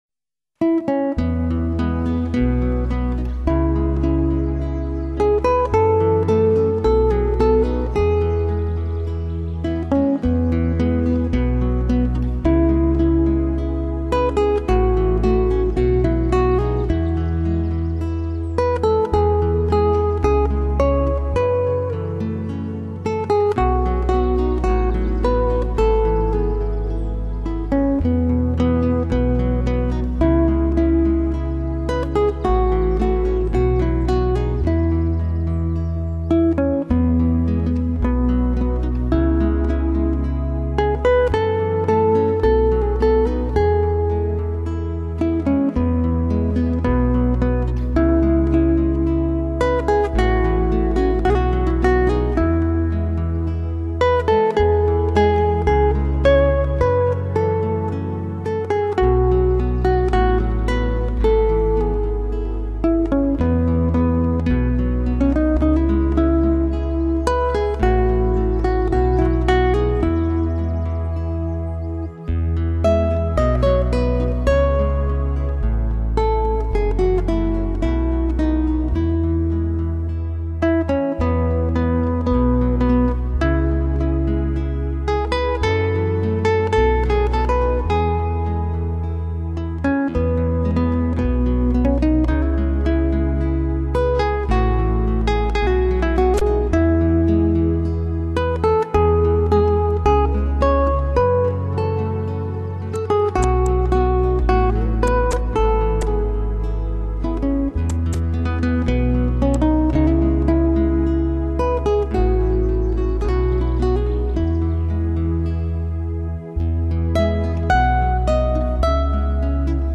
来自荷兰的音乐大师，自然烂漫是他们的演奏元素，田园诗般的感受让听者了解，我们追寻的是原汁原味的音乐享受．